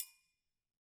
Triangle6-HitFM_v2_rr2_Sum.wav